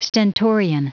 Prononciation du mot stentorian en anglais (fichier audio)
Prononciation du mot : stentorian